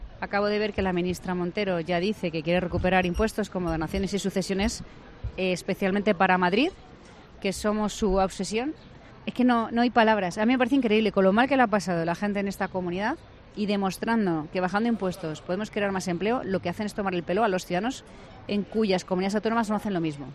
Isabel Díaz Ayuso valora en La Linterna esa amenaza de Hacienda de subir impuestos en Madrid